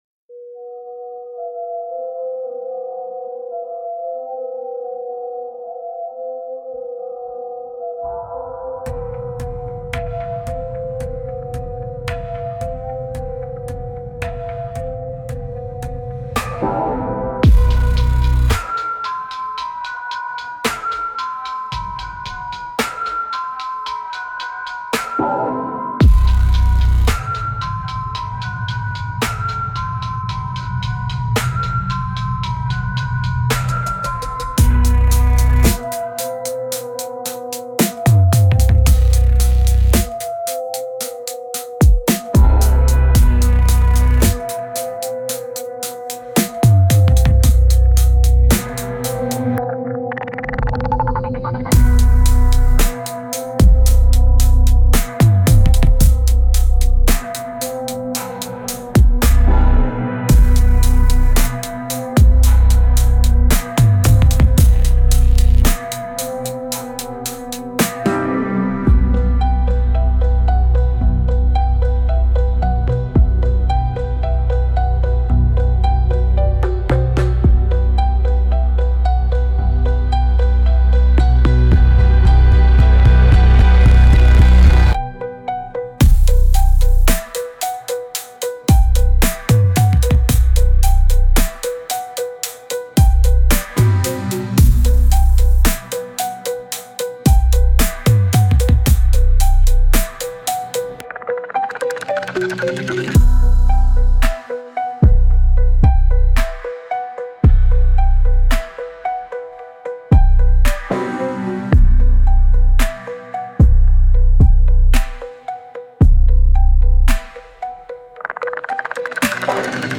Instrumental - RLMradio Dot XYZ- 4 mins